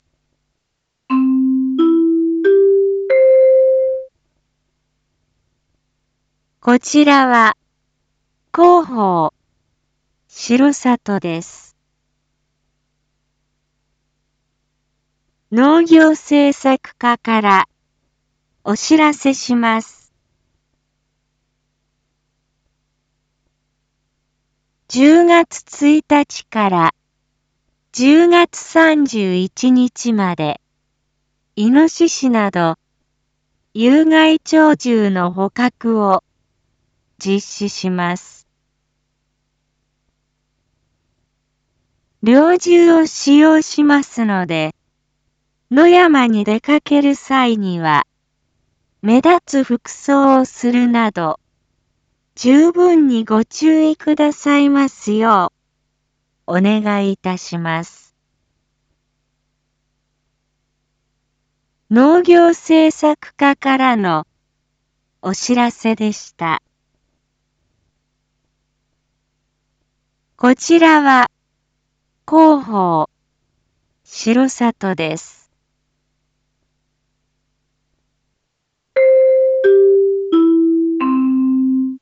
Back Home 一般放送情報 音声放送 再生 一般放送情報 登録日時：2024-09-29 07:01:23 タイトル：②有害鳥獣捕獲について インフォメーション：こちらは、広報しろさとです。